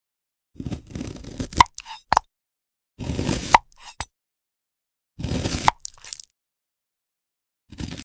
Cutting a Gem Strawberry | sound effects free download